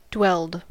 Uttal
Uttal US Okänd accent: IPA : /ˈdwɛlt/ Ordet hittades på dessa språk: engelska Ingen översättning hittades i den valda målspråket.